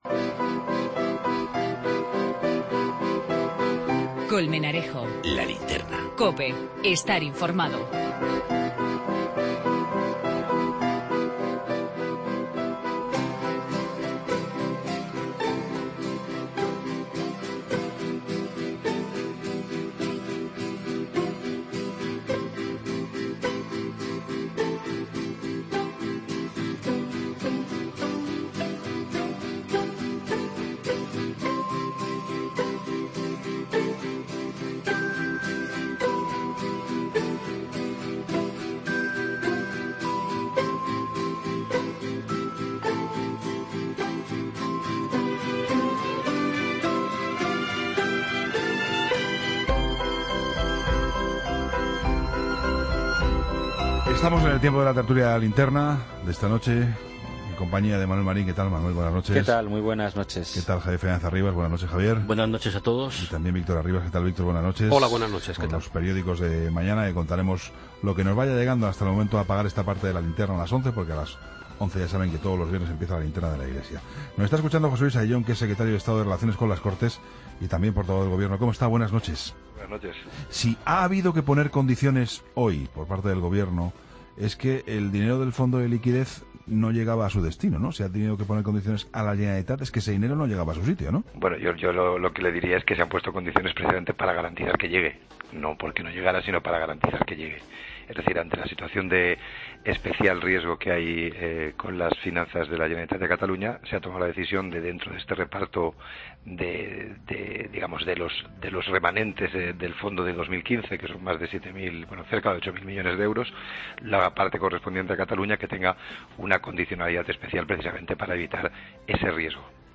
Entrevista al Secretario de Estado de Relaciones con las Cortes, José Luis Ayllón